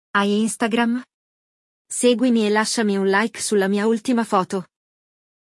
No nosso bate-papo, você vai escutar uma conversa autêntica entre falantes nativos do italiano, ajudando você a se acostumar com a sonoridade e o ritmo do idioma.